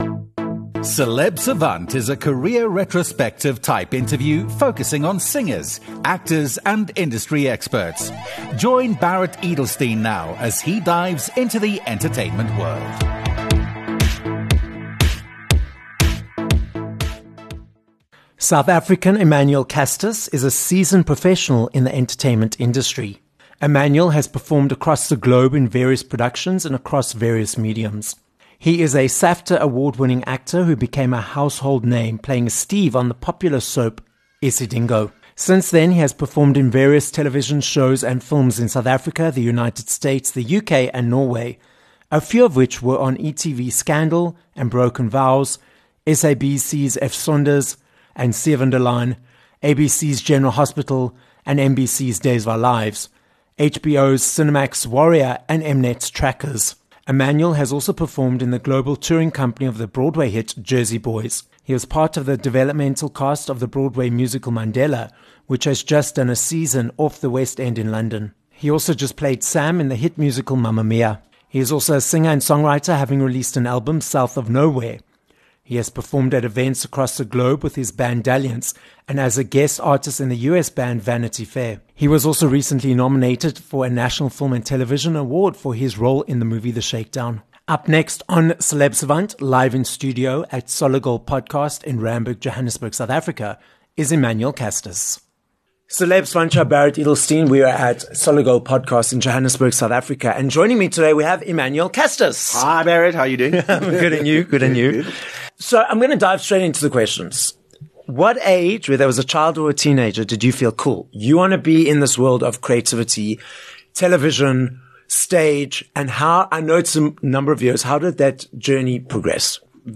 This episode was recorded live in studio at Solid Gold Podcasts, Johannesburg, South Africa.